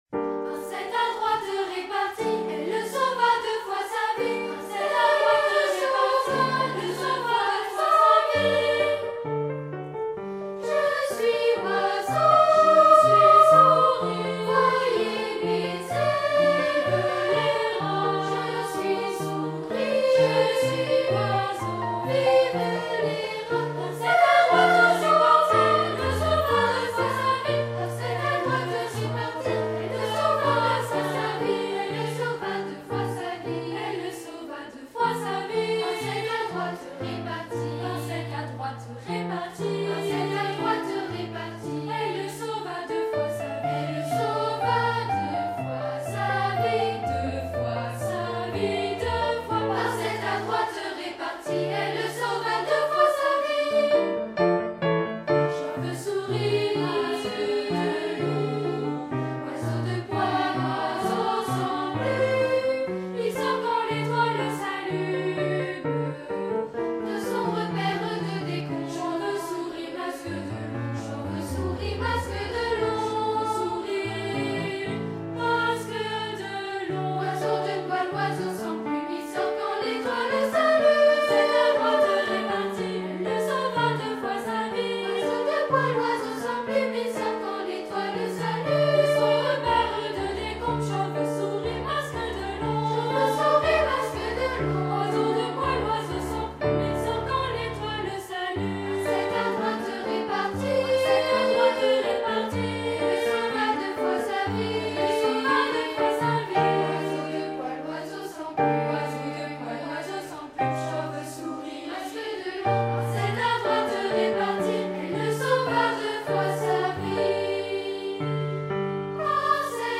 Cantate de la chauve-  souris